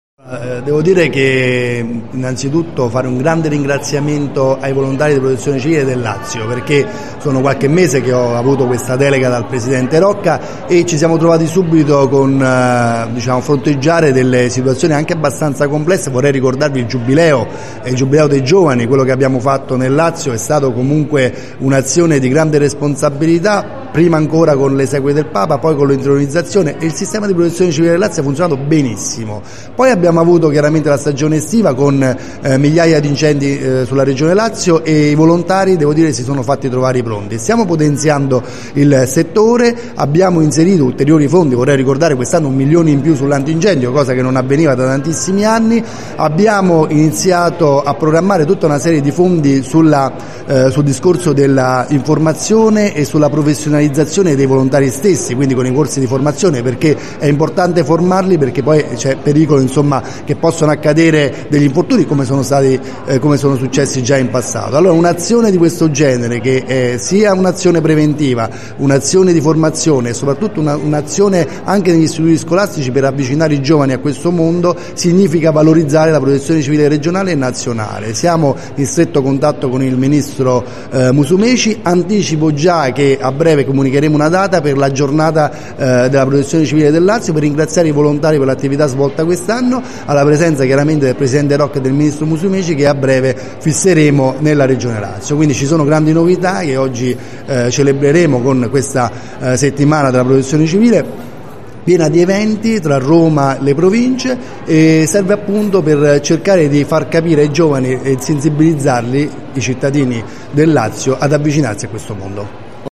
LATINA – Si è aperta con un convegno organizzato dalla Prefettura di Latina presso la Sala Conferenze della Facoltà di Economia del Polo Pontino della Sapienza Università di Roma, dal titolo “Prevenzione del rischio e buone pratiche di protezione civile”, la Settimana Nazionale della Protezione Civile che si svilupperà con una serie di appuntamenti in programma fino al 12 ottobre.